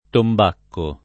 vai all'elenco alfabetico delle voci ingrandisci il carattere 100% rimpicciolisci il carattere stampa invia tramite posta elettronica codividi su Facebook tombacco [ tomb # kko ] s. m. (tecn.); pl. -chi — sim. il cogn.